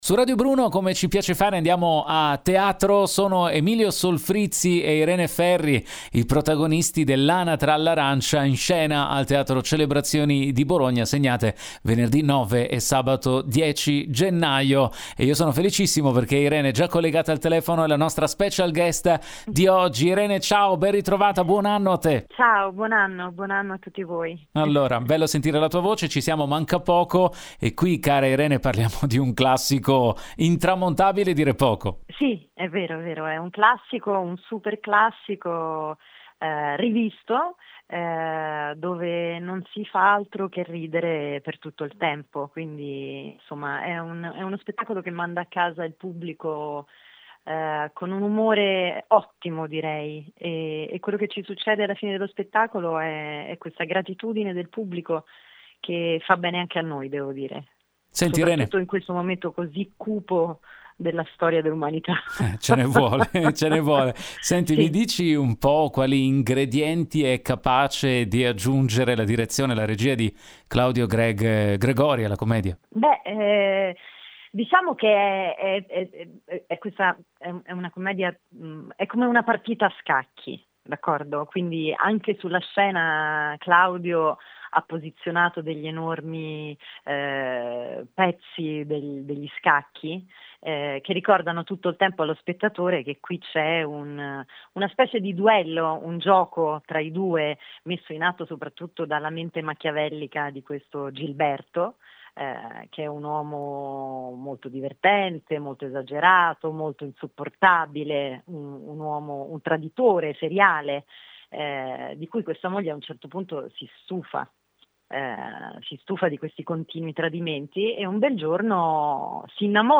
Home Magazine Interviste Irene Ferri presenta “L’anatra all’arancia”, in scena a Bologna